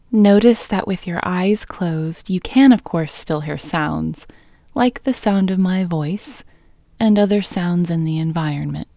Quietness-Female-2-1.mp3